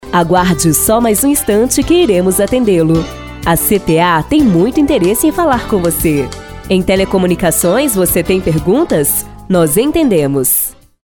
Mensagem de espera telefônica